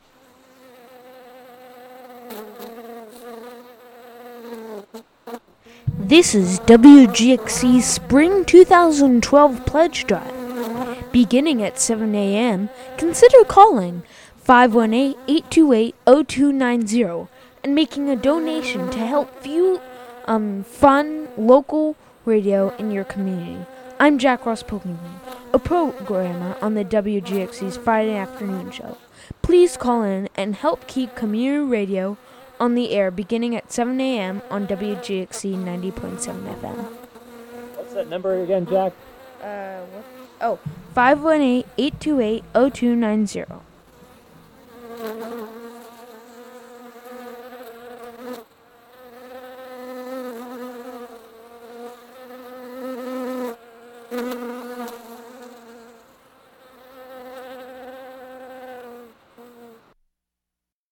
WGXC Spring 2012 Pledge Drive PSA for before 7 a.m. (Audio)